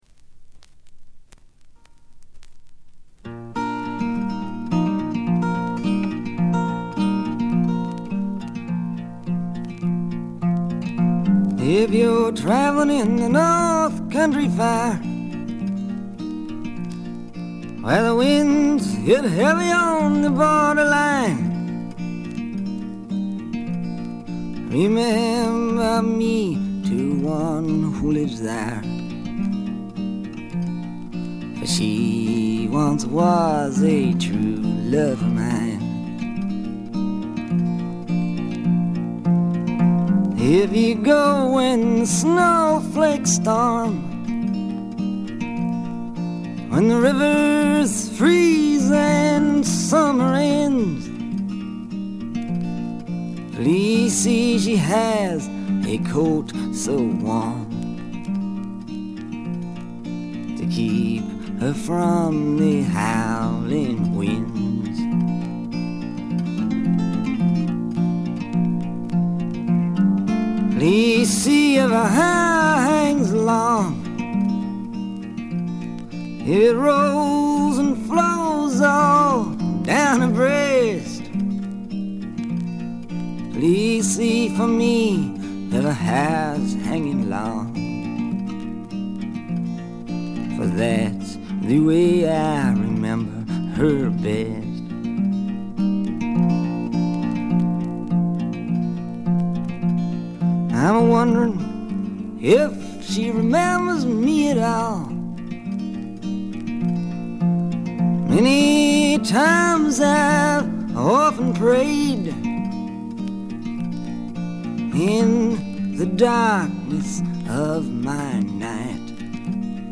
全体にバックグラウンドノイズ、チリプチ多め大きめですが音は見た目よりずっといい感じです。
試聴曲は現品からの取り込み音源です。